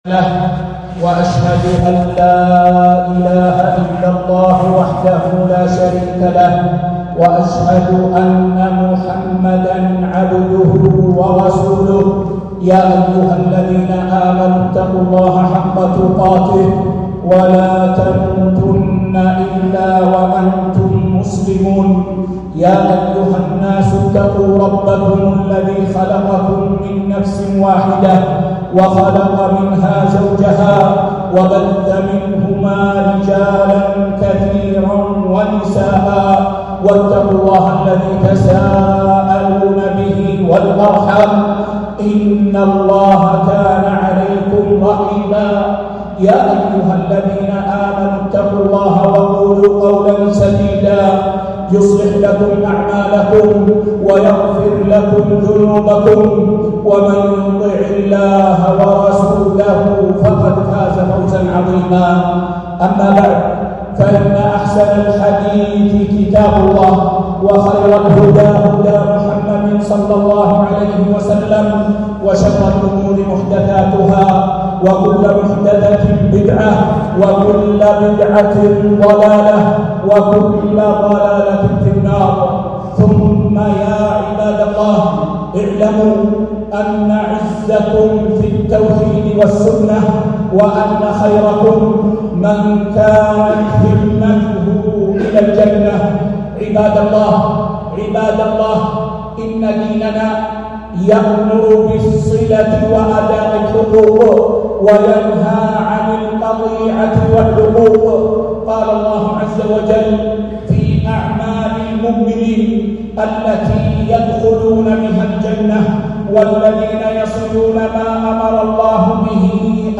خطبة - التحذير من قطيعة الرحم